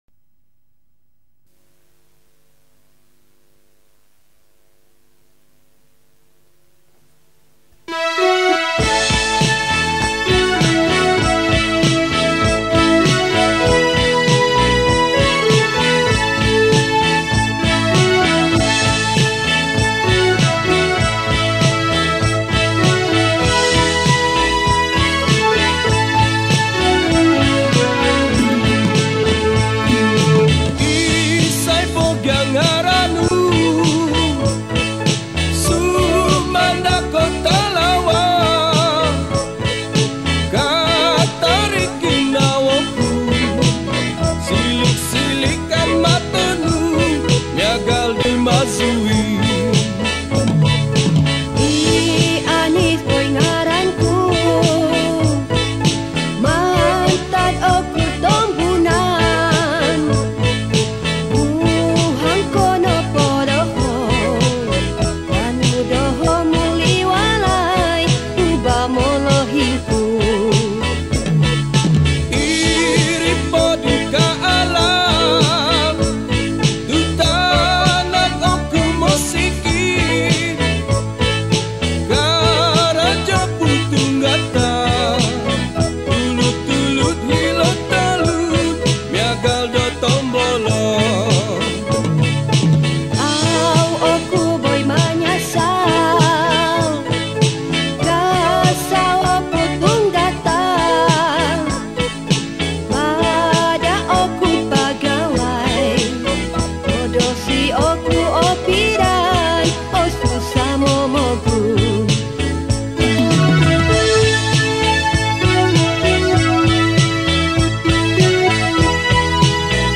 Kadazan Songs
Kadazan Evergreen Song